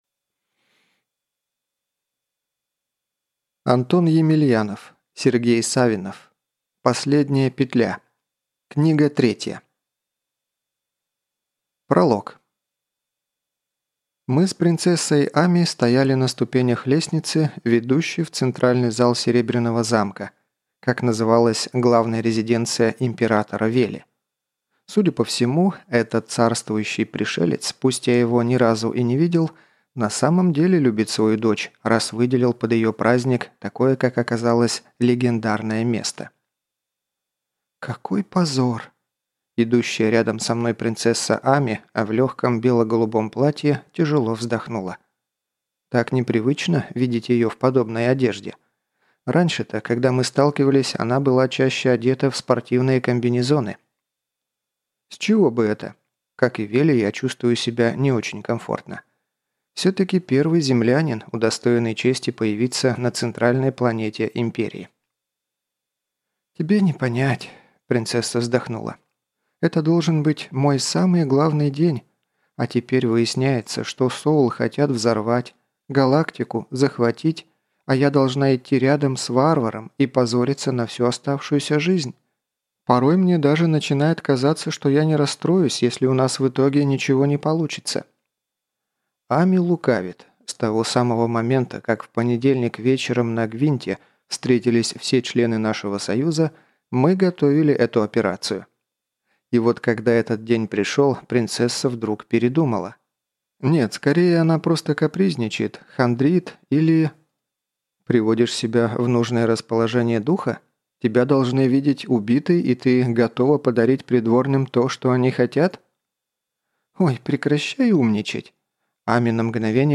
Аудиокнига Последняя петля. Книга 3 | Библиотека аудиокниг